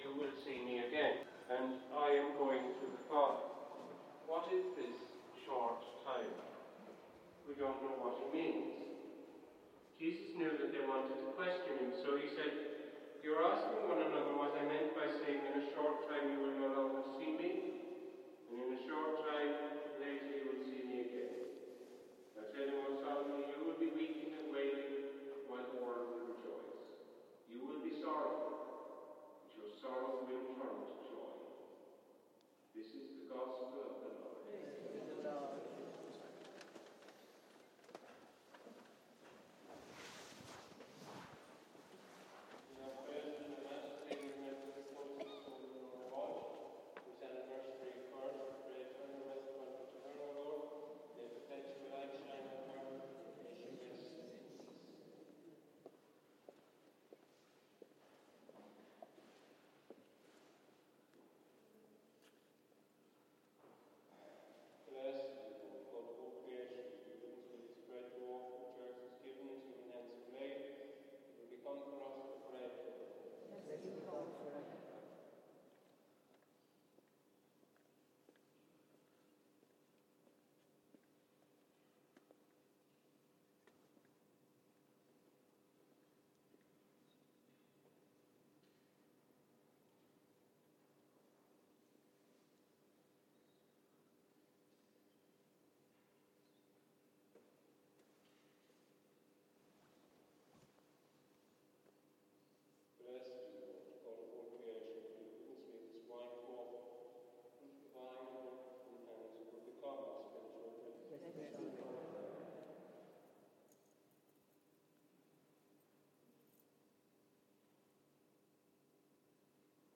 Mass at St. Peter and Paul Place, Cork
Sounds from Cork, Ireland